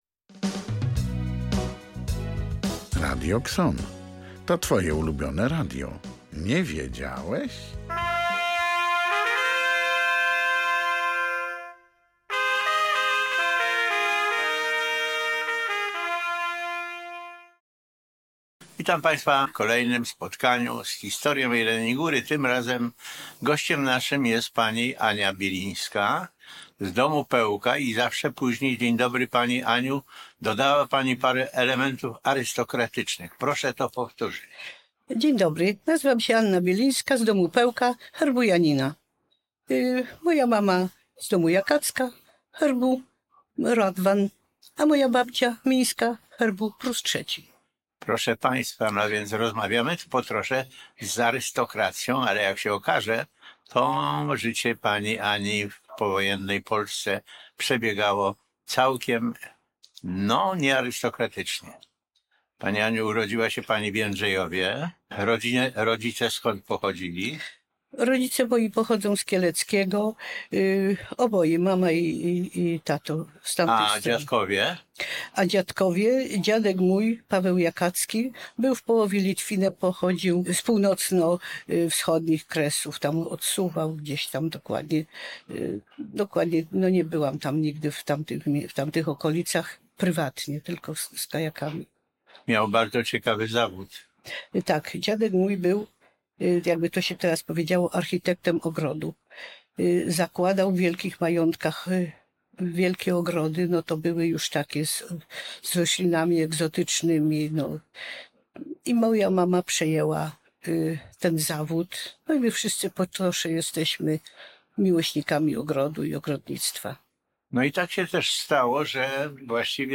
Oto rozmowa